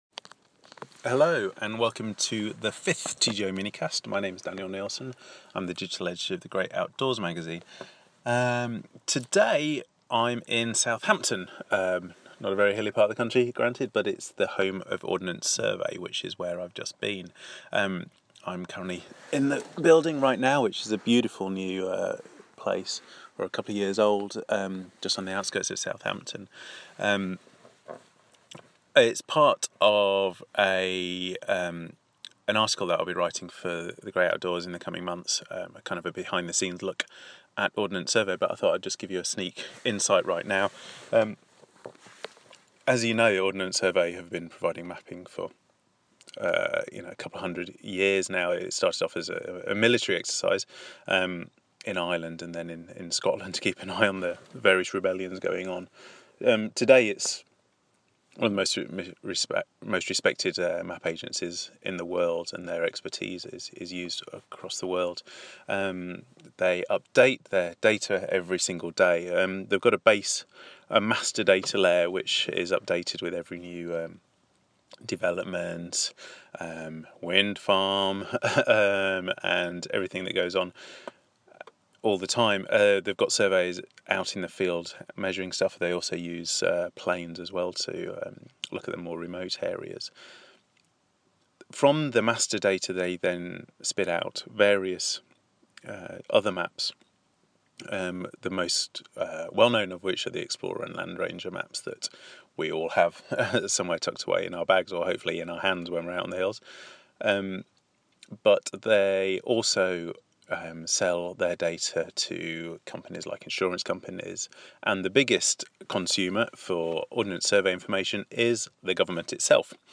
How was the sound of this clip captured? Today inside Ordnance Survey in Southampton researching a piece that will be in the magazine in the coming months.